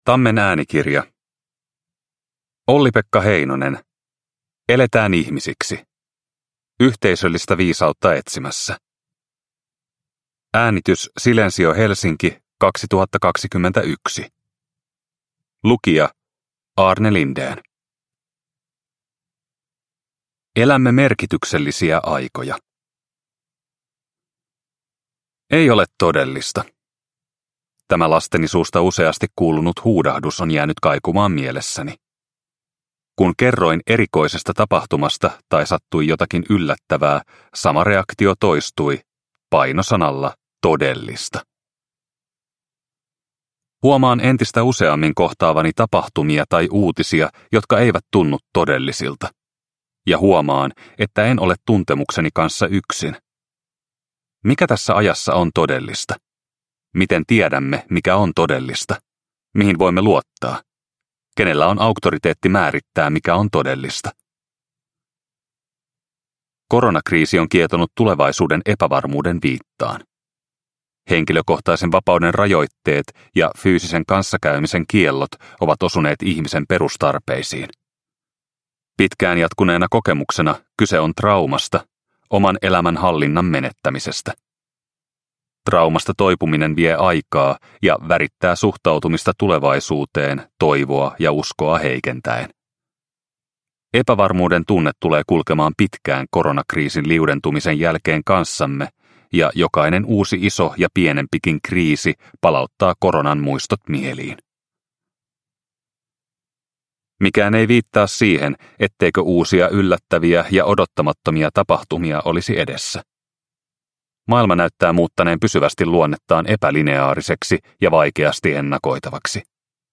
Eletään ihmisiksi – Ljudbok – Laddas ner